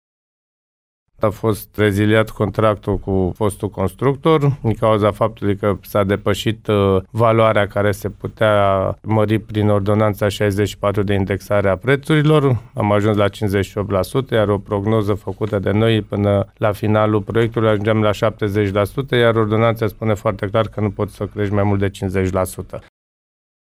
Primarul orașului Râșnov, Liviu Butnariu: